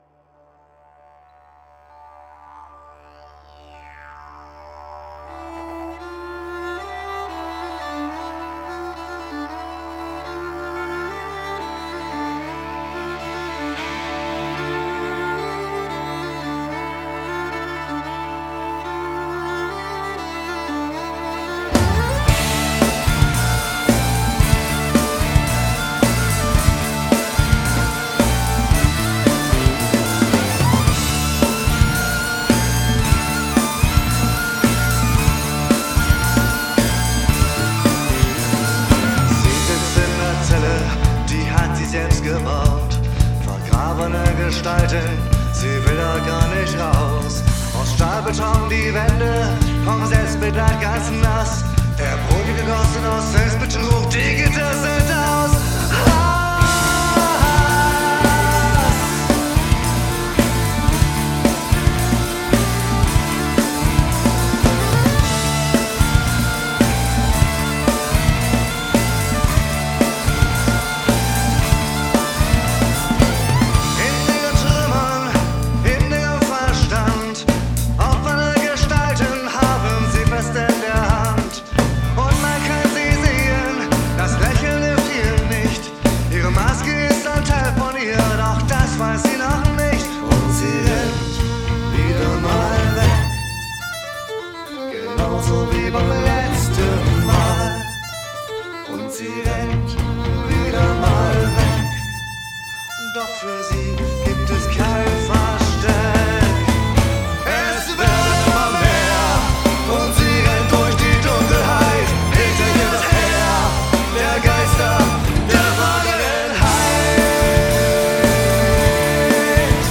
Whistles, Bodhrán, Didgeridoo, Gesang
Gitarre, eGitarre, Harp, Gesang
Geige, Gesang
eBass, Bass, Gesang
Drums